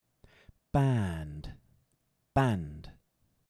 band – /bæːnd/ vs. banned – /bænd/